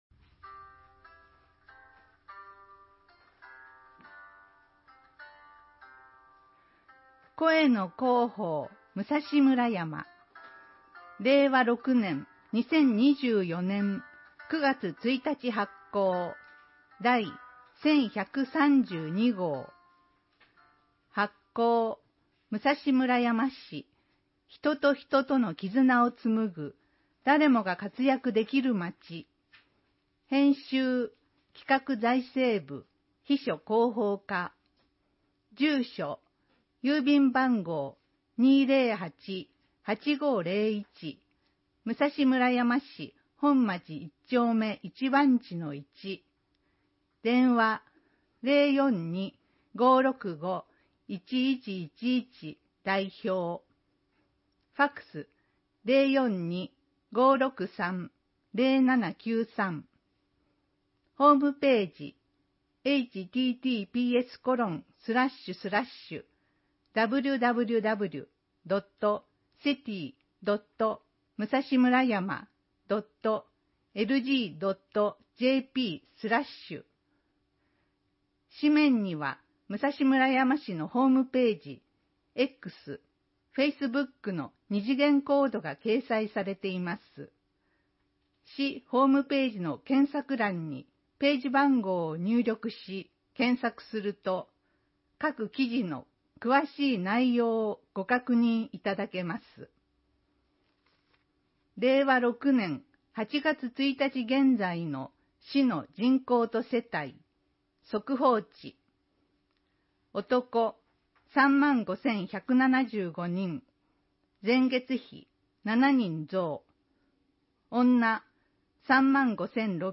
このページでは、視覚障害をお持ちの方のために「朗読サークルむらやま」のみなさんが朗読した市報の音声ファイル（MP3）を公開しています。